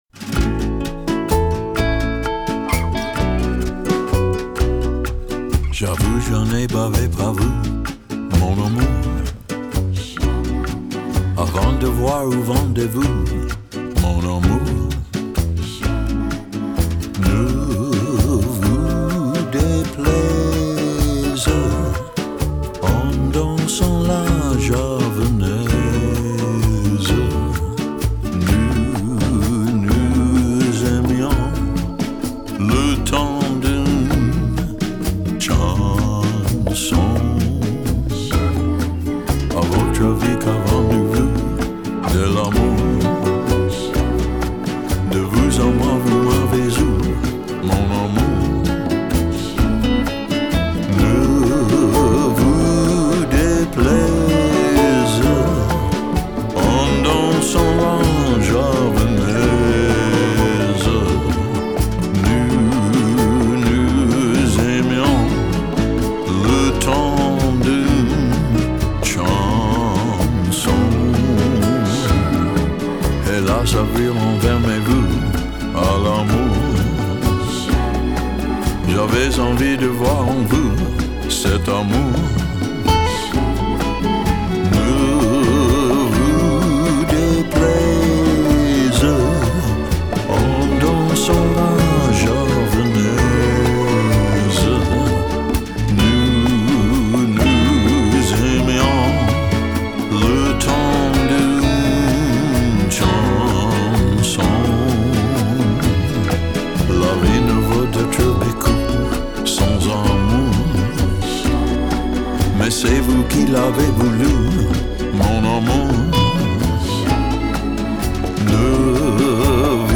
Genre: Vintage Lounge, Chanson, Vocal Jazz